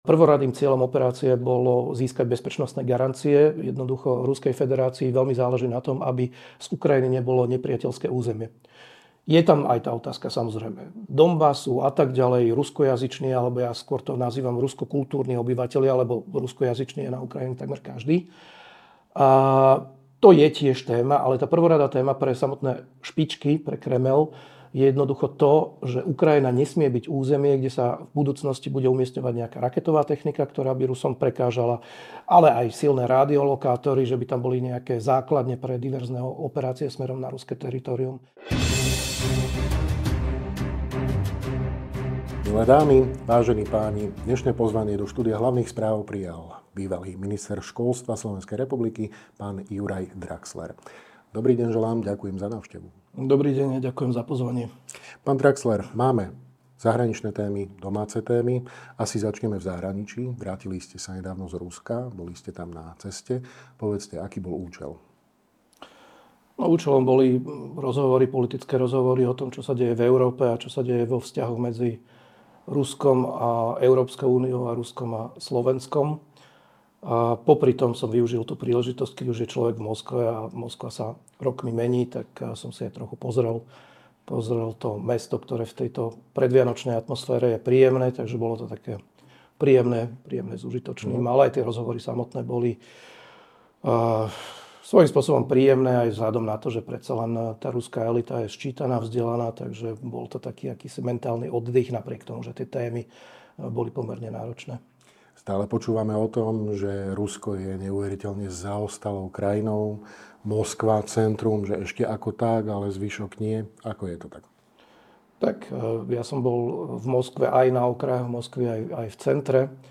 Odpovede na tieto otázky, ale aj hodnotenie nedávnych vulgárnych konfliktov v našom parlamente, budete počuť vo videorozhovore s bývalým ministrom školstva, vedy, výskumu a športu SR, Jurajom Draxlerom.